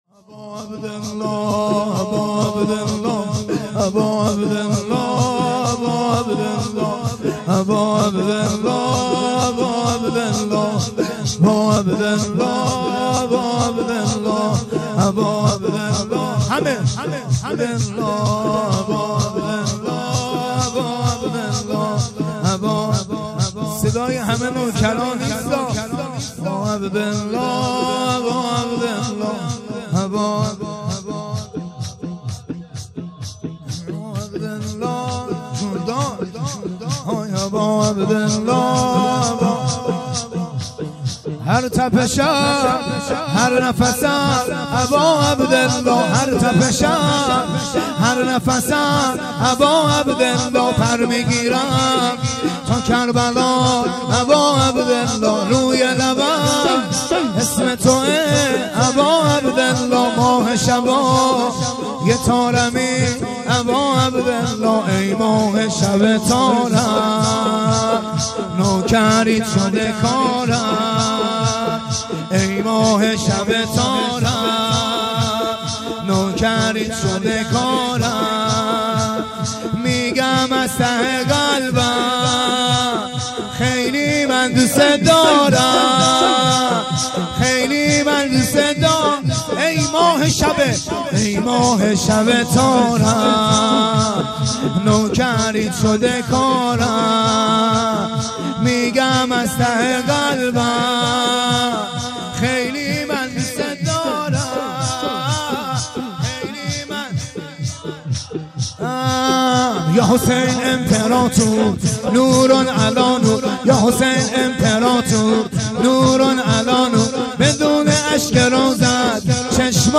شور-هر-تپشم-هر-نفسم-اباعبدالله.mp3